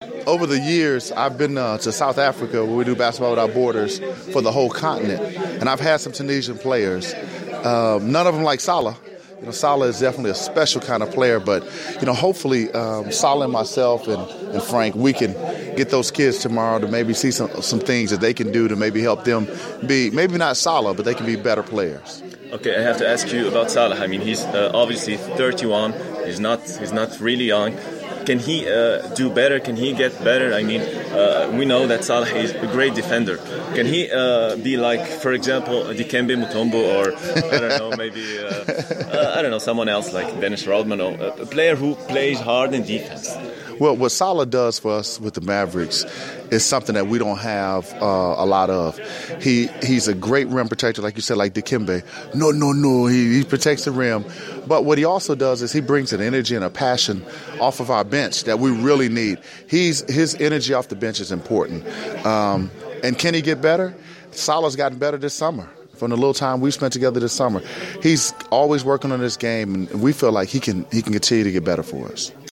عقد اللاعب الدولي لكرة السلة صالح الماجري ندوة صحفية بمقر السفارة الأمريكية بتونس للحديث حول دورة "NBA CAMP" التدريبية التي تنظمها مؤسسة صالح الماجري بالتعاون مع رابطة الدوري الأمريكي للمحترفين في تونس لأول مرة .